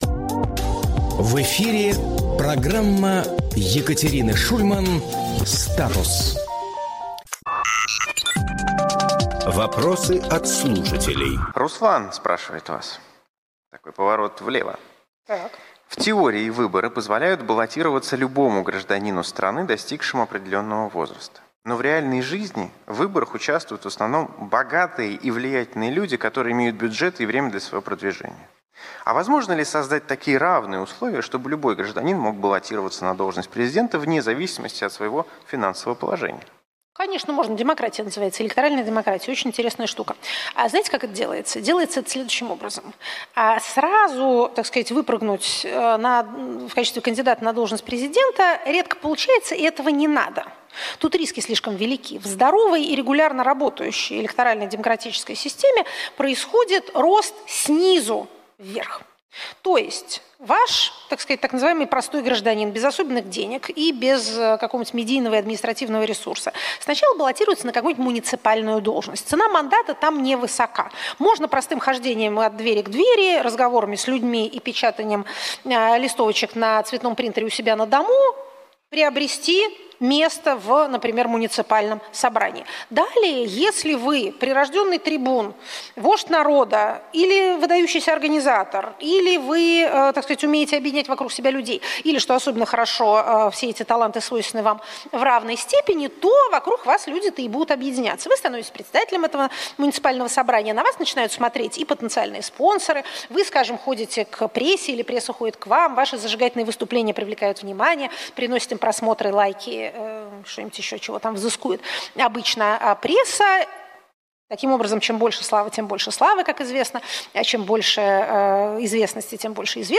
Екатерина Шульманполитолог
Фрагмент эфира от 02.09.25